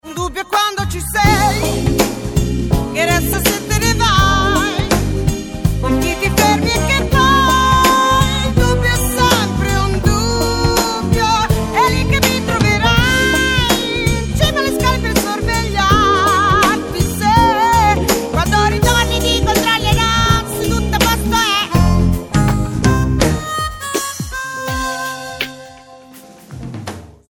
piano fender e synths
chitarra